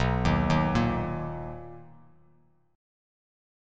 Listen to BM7 strummed